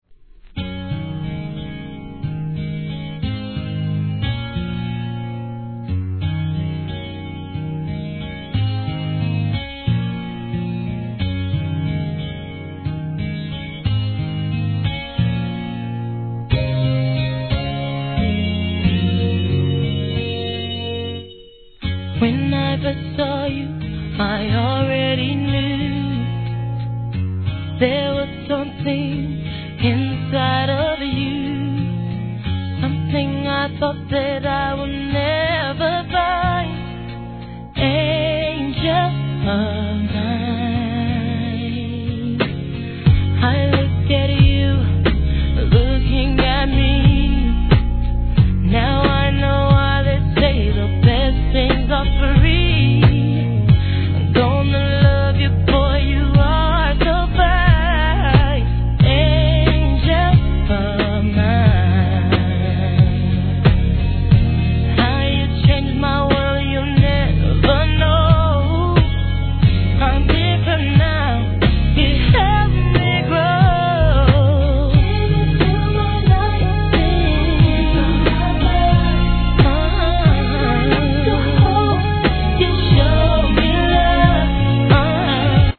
HIP HOP/R&B
アコースティックの胸キュン・メロディ〜に、雑多の仕事で疲れた僕を癒してくれます♪